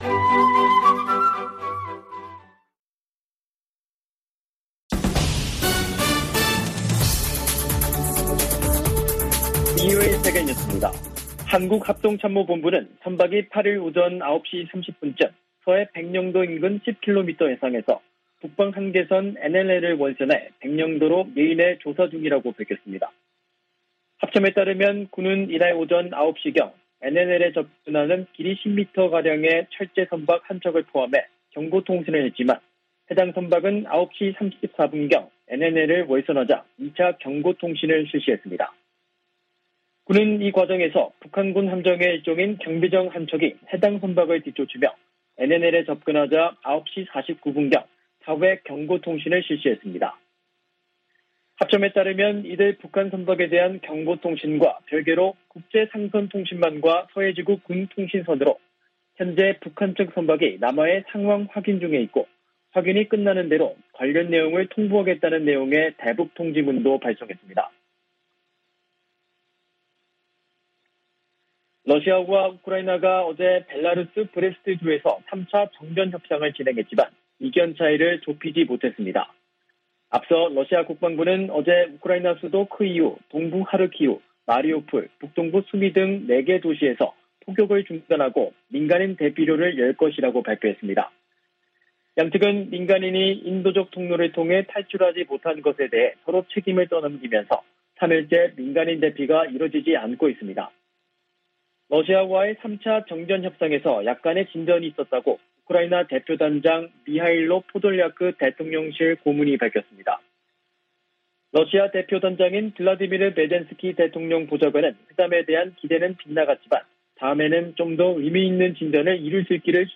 VOA 한국어 간판 뉴스 프로그램 '뉴스 투데이', 2022년 3월 8일 2부 방송입니다. 북한의 탄도미사일 발사에 대한 유엔 안보리의 공식 대응이 다시 무산됐습니다. 북한 미사일 도발 국제사회 비난이 커지는 가운데 북-중-러 삼각 연대가 강화되는 양상을 보이고 있습니다. 북한이 영변과 강선 등지에서 핵 활동을 지속하는 징후가 있다고 국제원자력기구(IAEA) 사무총장이 밝혔습니다.